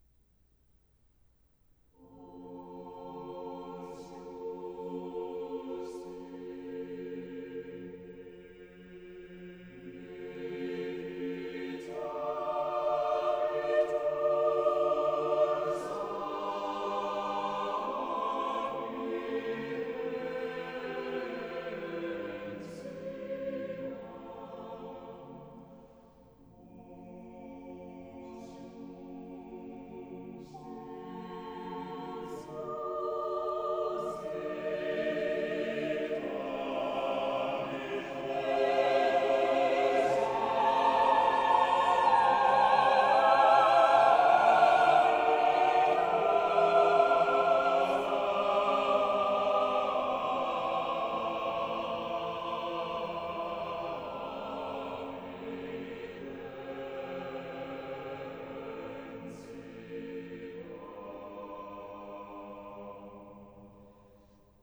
Chiaroscuro.wav